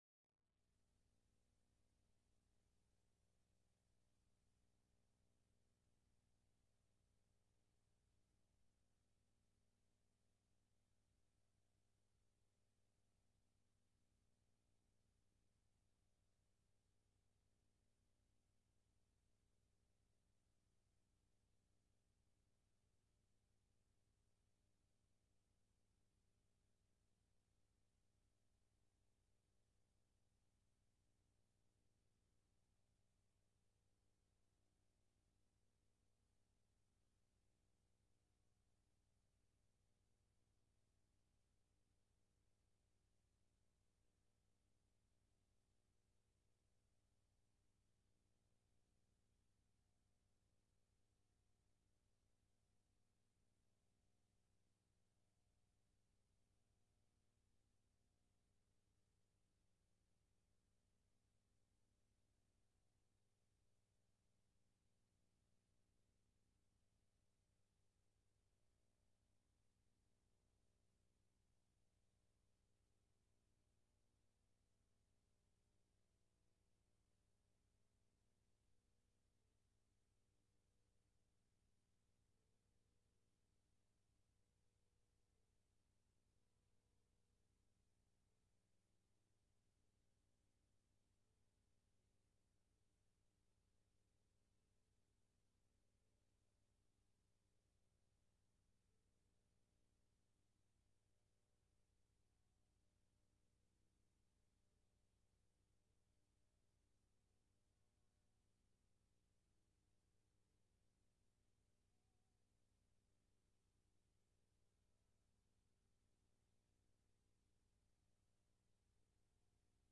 6_6_21-sermon.mp3